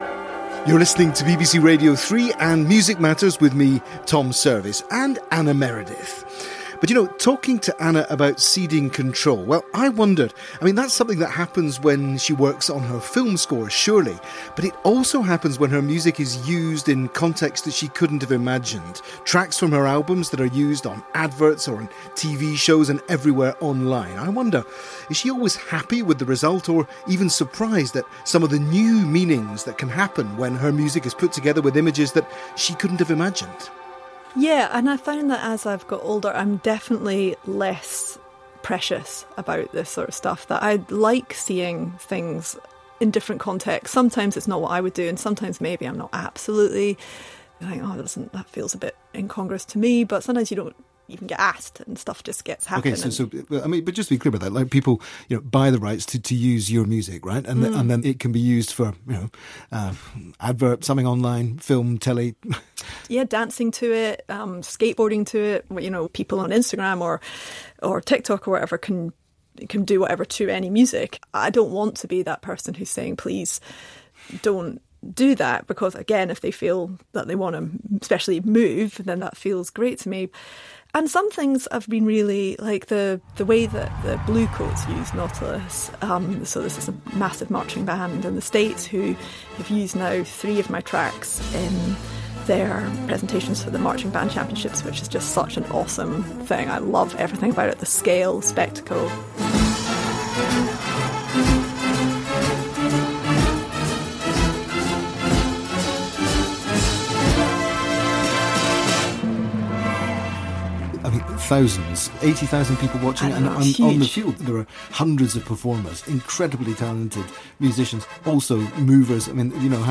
On Saturday Anna Meredith joined Tom Service on the UK radio program Music Matters, hosted on BBC Radio 3. She discussed Bluecoats’ use of three of her titles, focusing on the 2022 use of Nautilus in Riffs & Revelations.